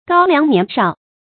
膏粱年少 gāo liáng nián shǎo 成语解释 指富贵人家的子弟。
ㄍㄠ ㄌㄧㄤˊ ㄋㄧㄢˊ ㄕㄠˋ